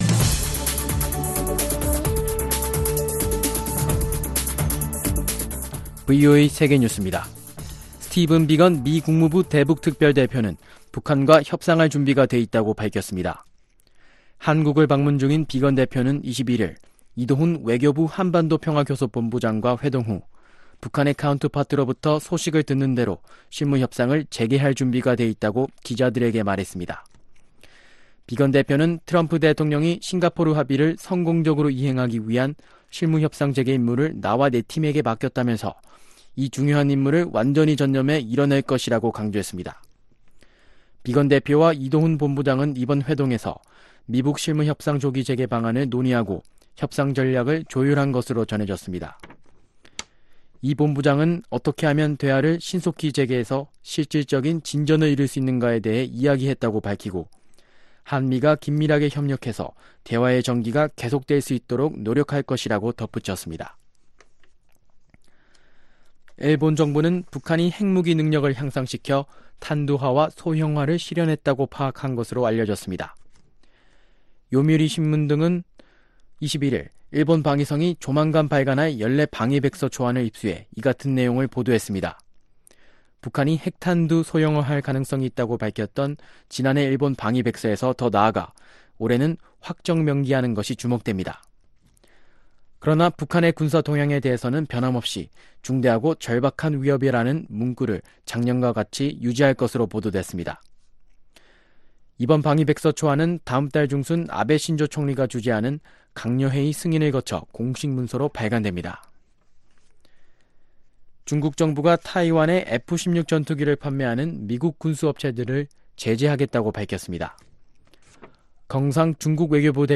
VOA 한국어 아침 뉴스 프로그램 '워싱턴 뉴스 광장' 2019년 8월 22일방송입니다. 한국을 방문한 스티븐 비건 국무부 대북특별대표는 북한으로부터 소식을 듣는 대로 실무 협상을 시작할 준비가 돼 있다고 말했습니다. 국제원자력기구(IAEA)는 북한이 지난해에도 핵 개발을 지속한 것은 유엔 안보리 대북 결의 위반이라며 깊은 유감을 표시했습니다.